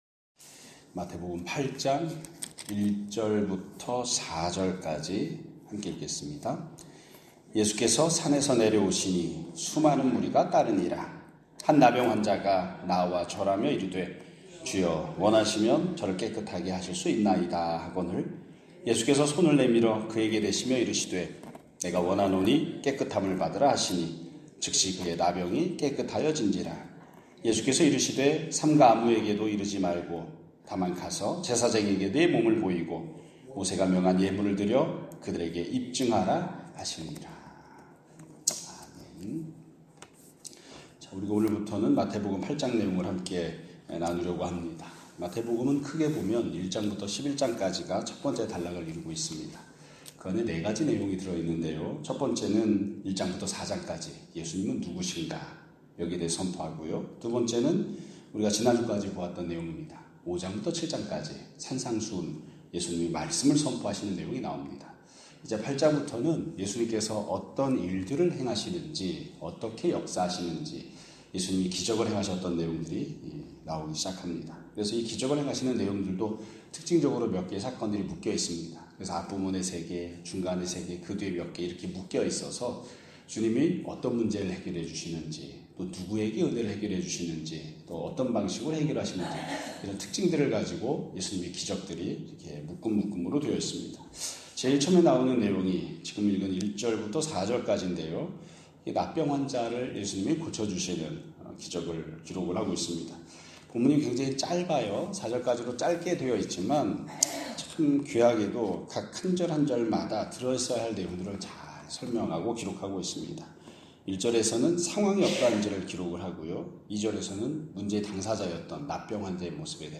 아래 성경본문 pdf 화일 윗편에 설교음성화일이 첨부되어 있습니다.
2025년 7월 7일(월요 일) <아침예배> 설교입니다.